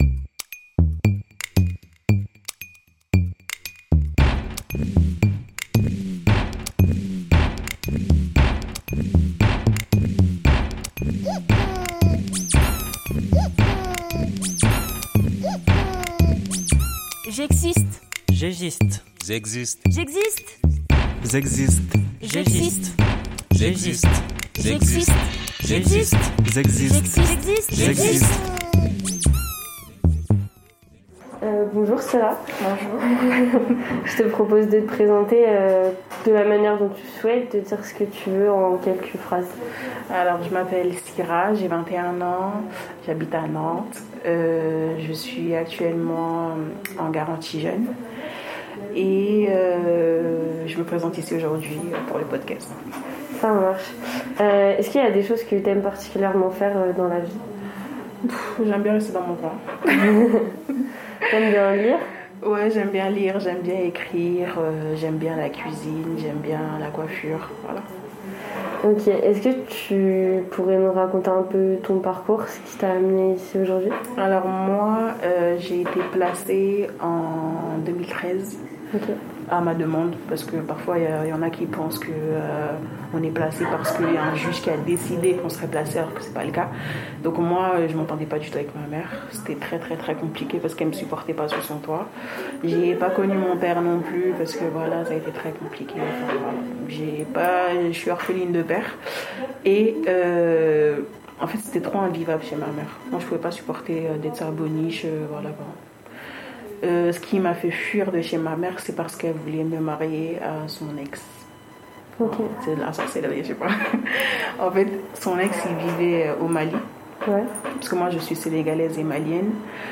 Témoignages recueillis au micro
Jingle réalisé par les adhérents de l’ADEPAPE Repairs ! 44